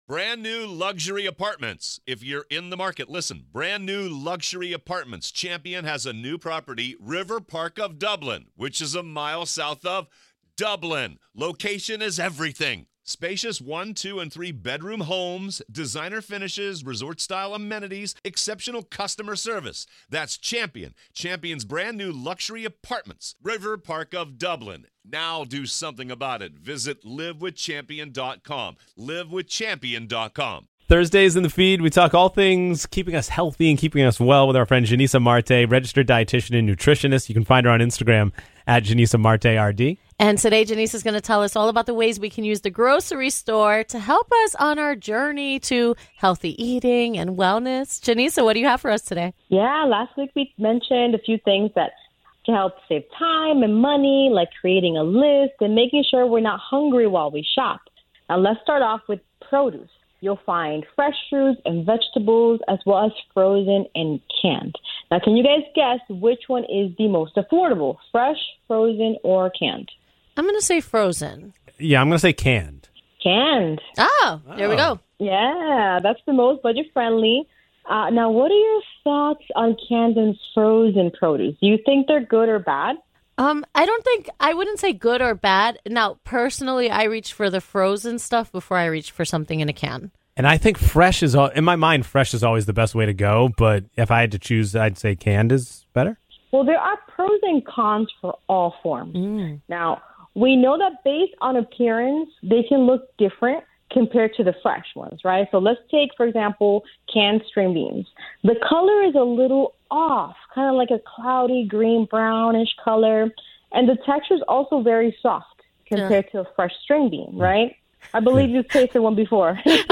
chat with an expert about all things health and wellness